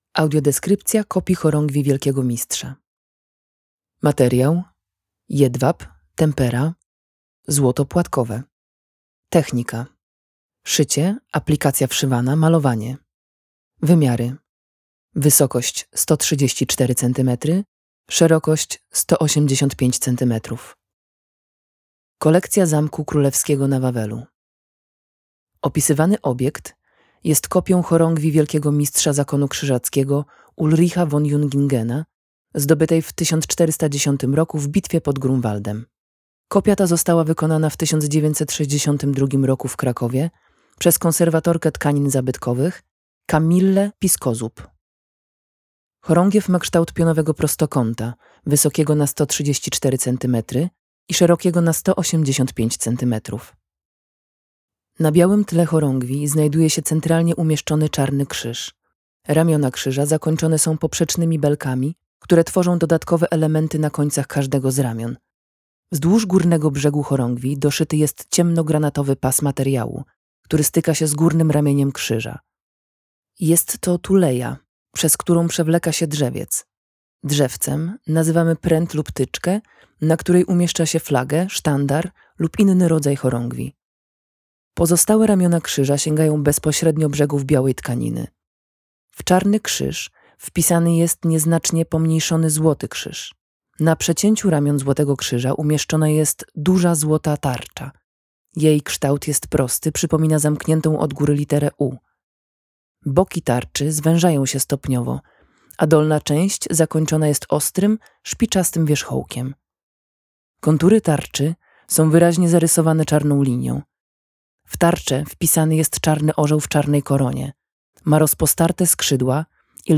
choragiew-aleksandra-audiodeskrypcja.mp3